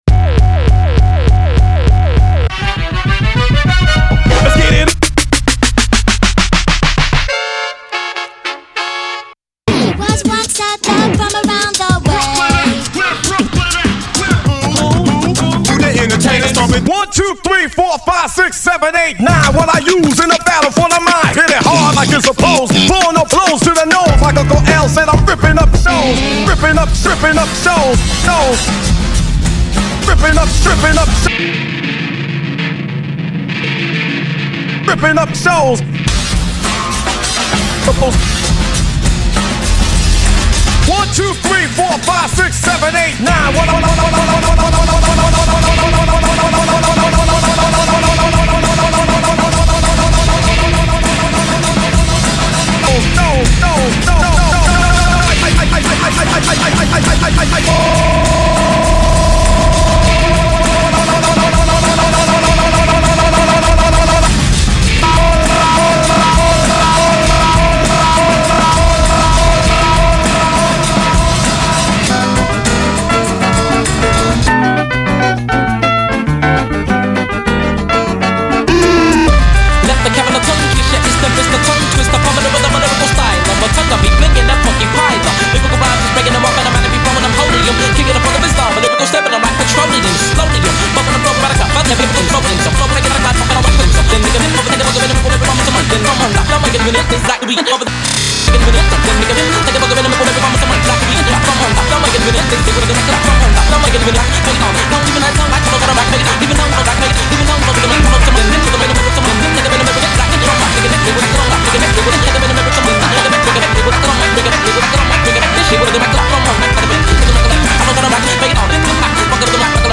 vibrant